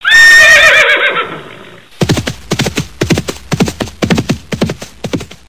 horse-sound.mp3